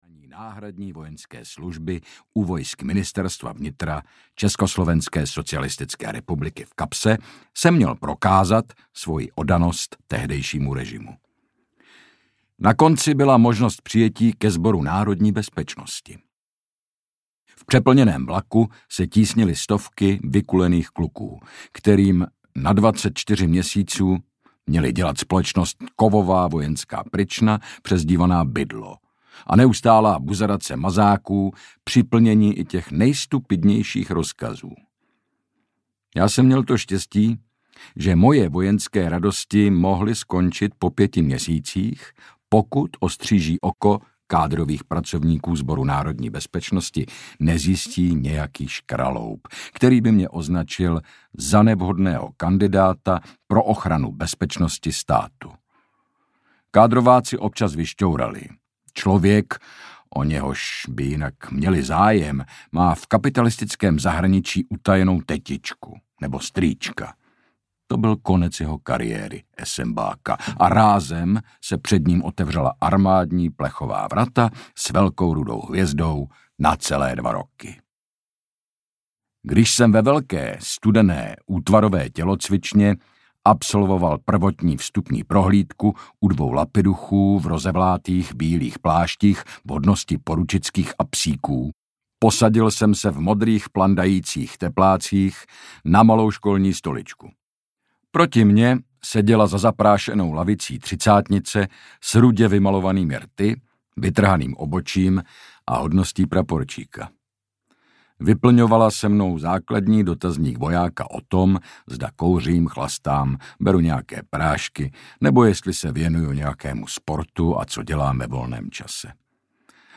Rapl z Bartolomějský audiokniha
Ukázka z knihy
• InterpretHynek Čermák